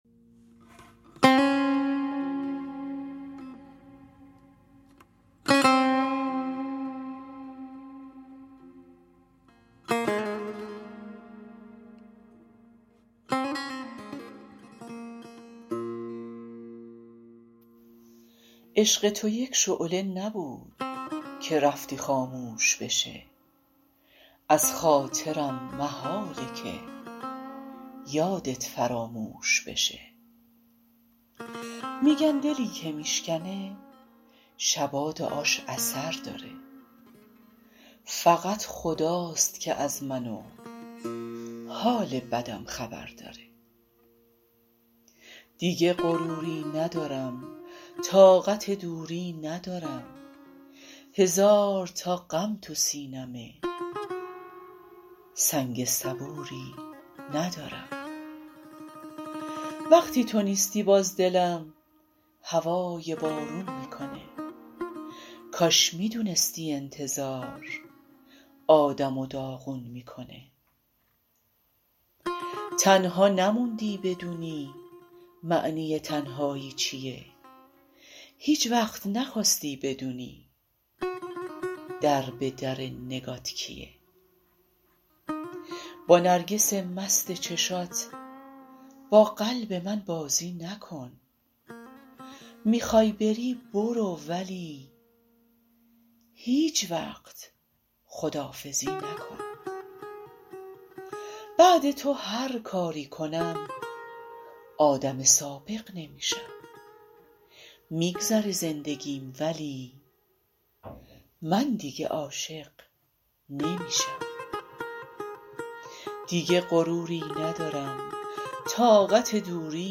نوازنده سه تار
حس وحال ترانه و سه تار نوازی عااالی بود دکلمه هم خوب بود.